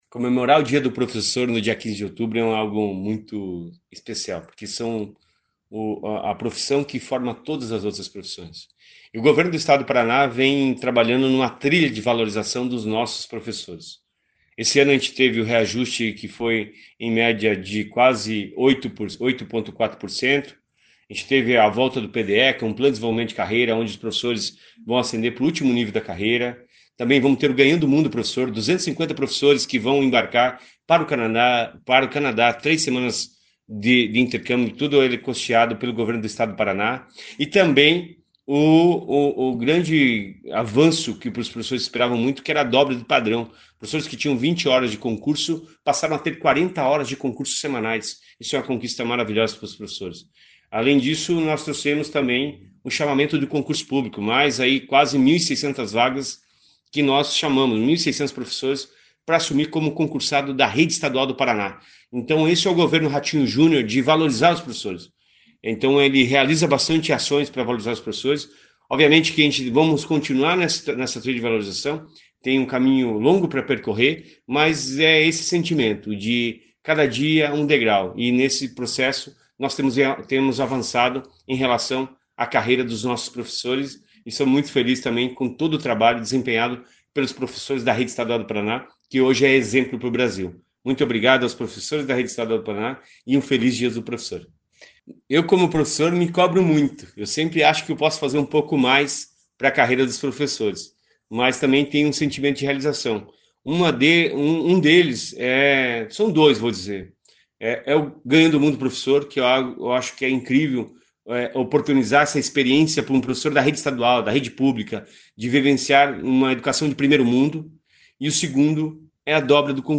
Sonora do secretário Estadual da Educação, Roni Miranda, sobre o Dia do Professor